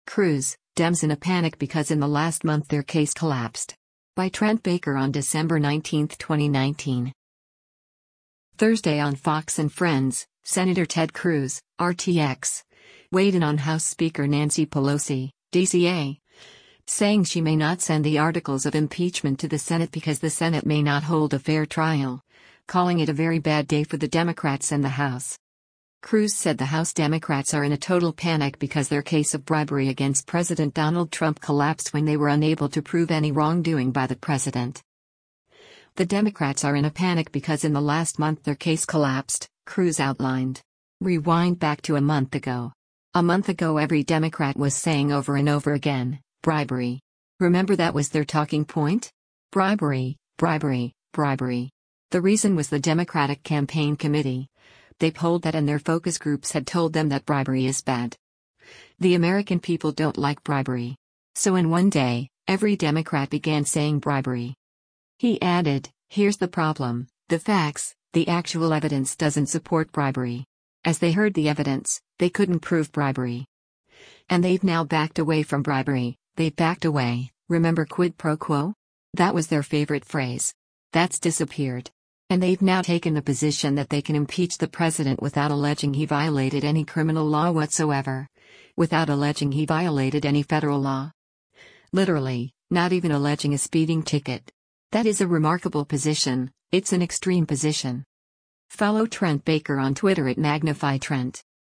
Thursday on “Fox & Friends,” Sen. Ted Cruz (R-TX) weighed in on House Speaker Nancy Pelosi (D-CA) saying she may not send the articles of impeachment to the Senate because the Senate may not hold a fair trial, calling it “a very bad day” for the Democrats and the House.